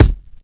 kits/RZA/Kicks/WTC_kYk (17).wav at main